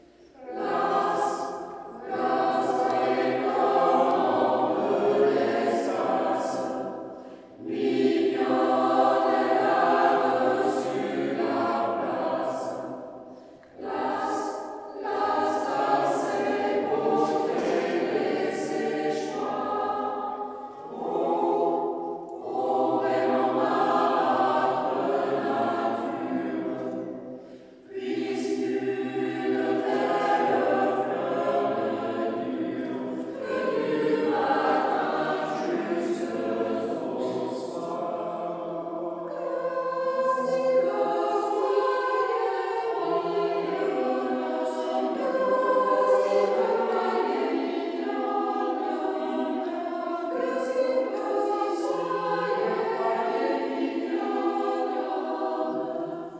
Concerts du 10 juin 2023 au Temple de Rambouillet et 11 juin 2023 en l’église St Nicolas de Saint Arnoult en Yvelines
Chœur a cappella :